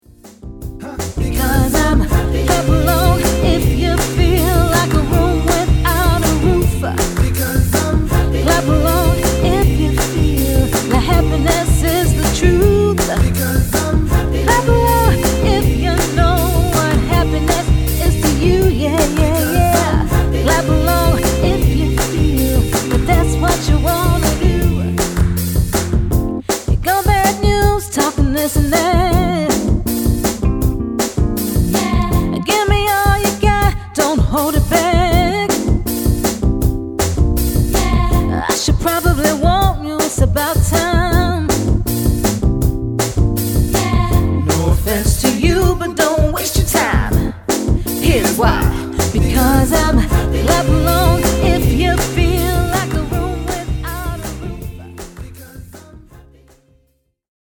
R'n'B girl group